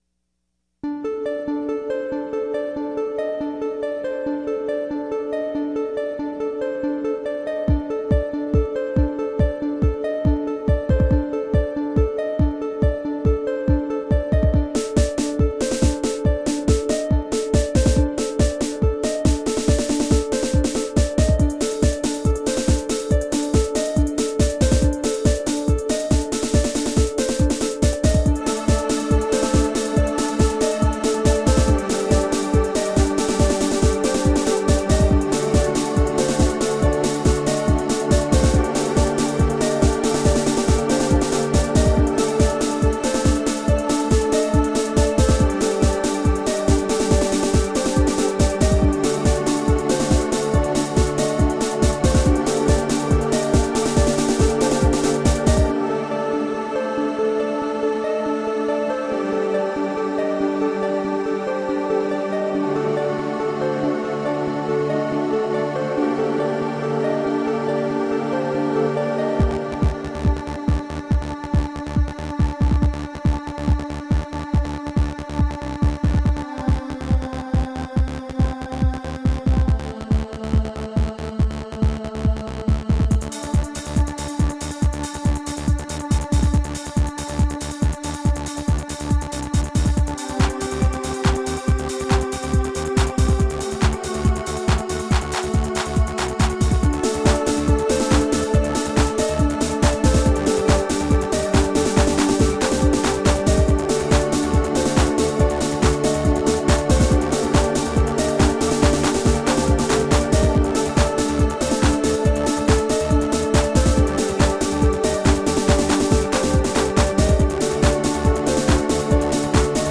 Melodic electronica.
Tagged as: Electronica, Other, IDM, Prog Rock